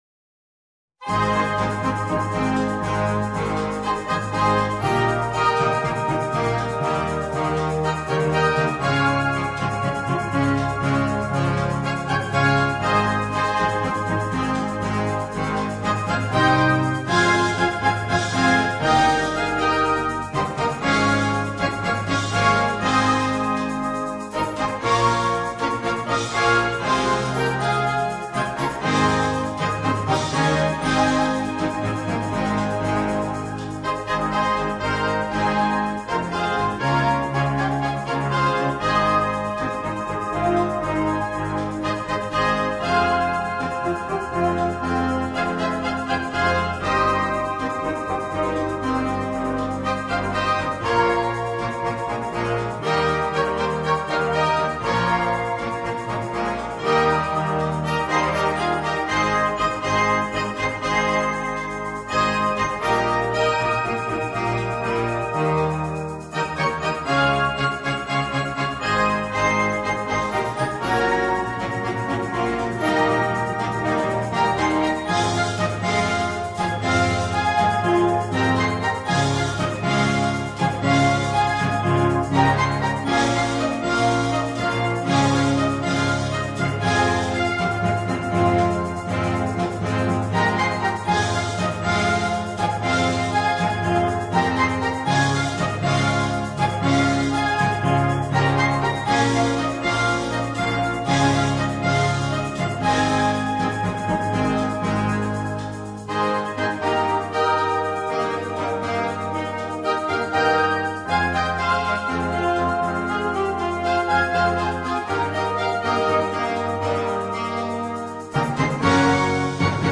Un brano da concerto
MUSICA PER BANDA